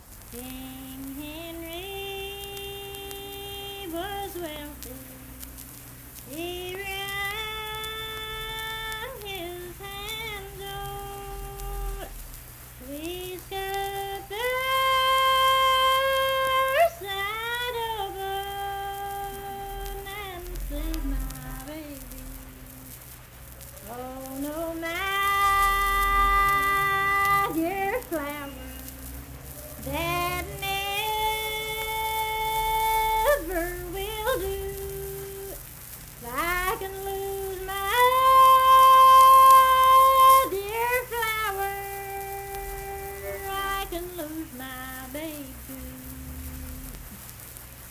Unaccompanied vocal music
Verse-refrain, 2(4).
Voice (sung)